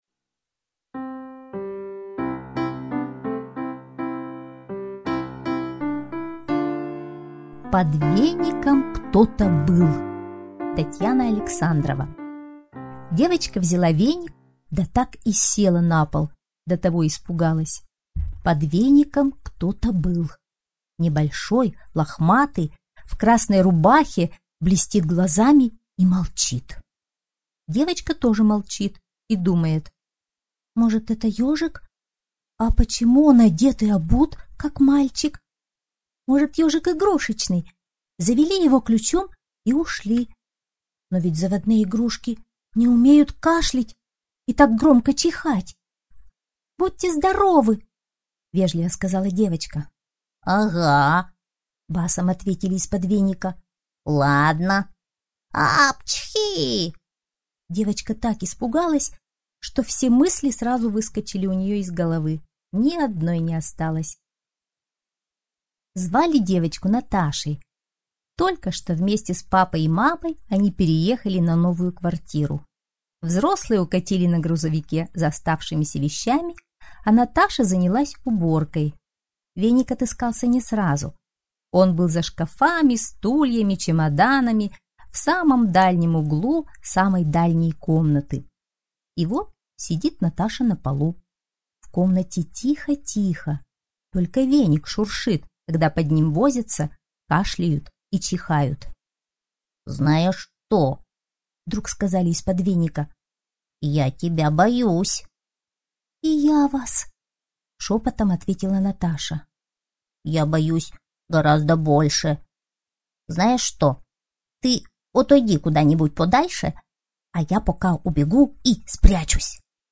Под веником кто-то был - аудиосказка Александровой - слушать онлайн